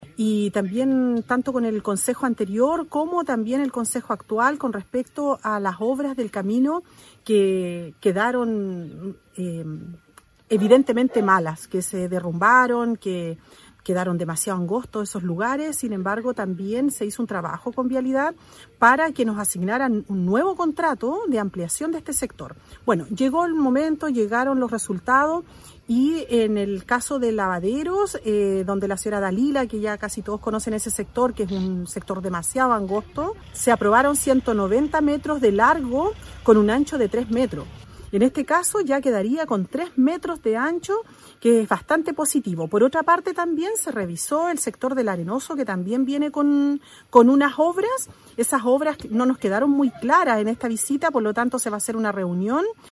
Así lo manifiesta la alcaldesa Carmen Juana Olivares.